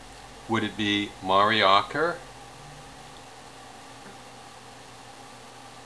HOW DO YOU SAY THAT NAME?
Listen for yourself  and compare your pronunciation!